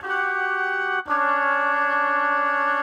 Index of /musicradar/gangster-sting-samples/85bpm Loops
GS_MuteHorn_85-GD.wav